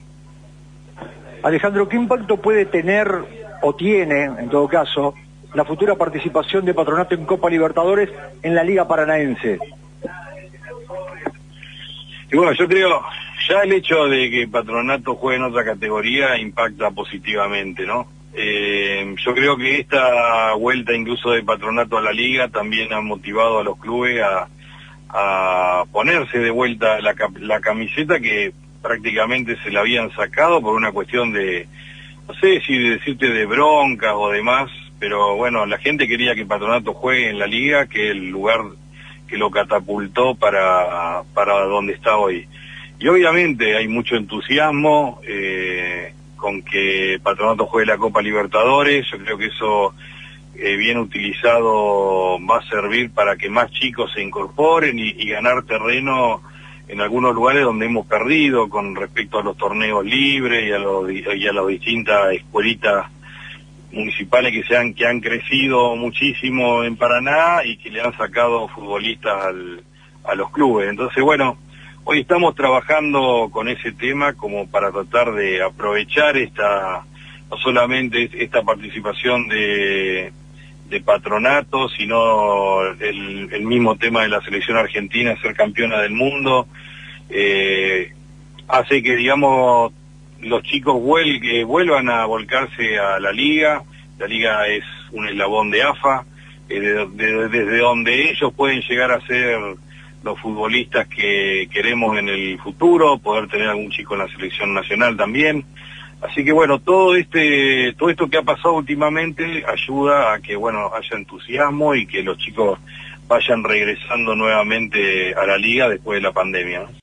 Linda charla